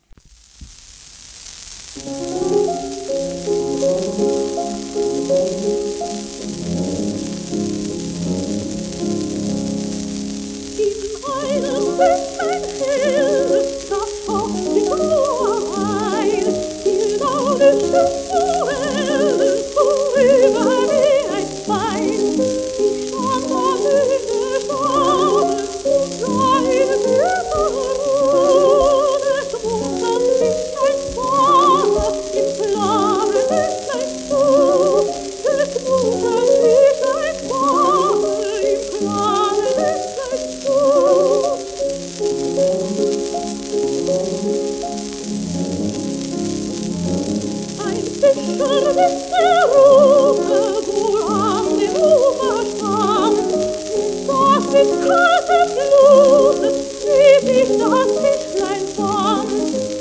1946年録音